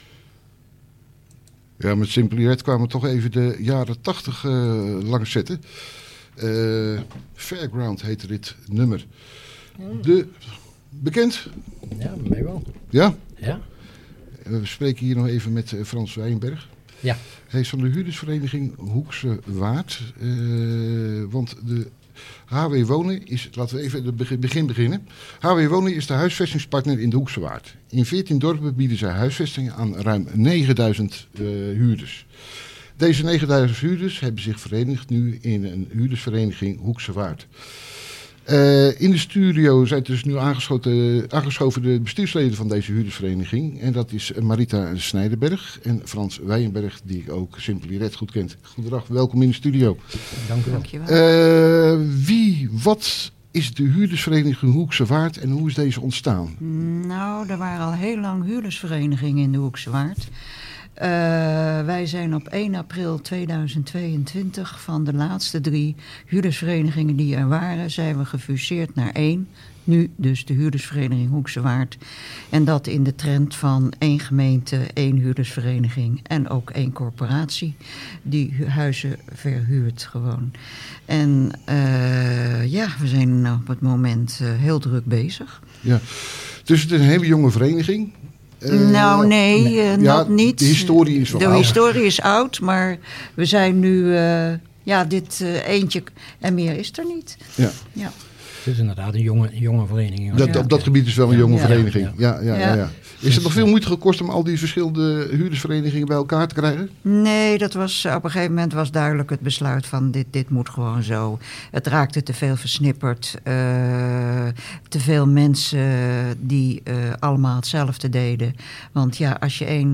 Een afvaardiging van het bestuur is op bezoek geweest bij Omroep Hoeksche Waard op 27 mei 2024. Het interview is te beluisteren door te klikken op deze link.